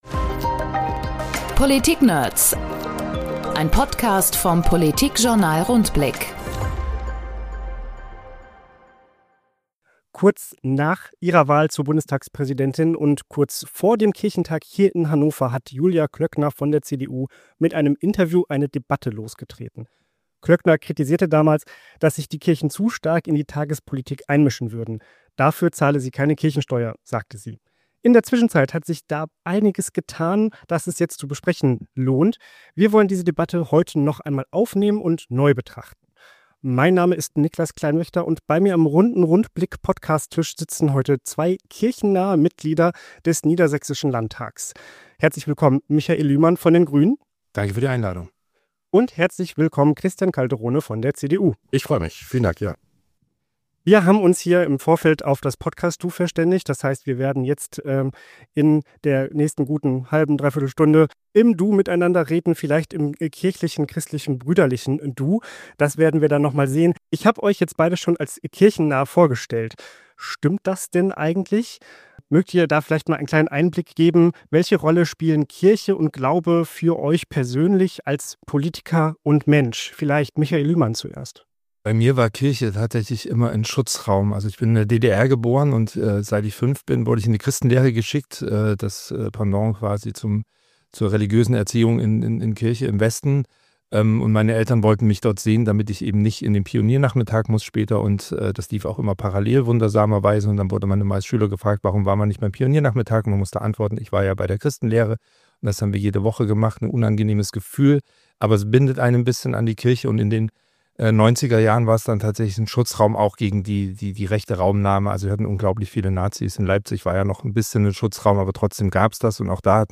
Christian Calderone (CDU) und Michael Lühmann (Grüne) im Streitgespräch Die beiden Abgeordneten Christian Calderone (CDU) und Michael Lühmann (Grüne) trennen politisch Welten. Ihr christlicher Glaube aber verbindet sie. Beurteilen sie deshalb auch die Rolle der Kirchen gleich?